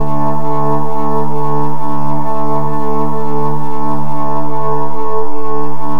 SAWED     -R.wav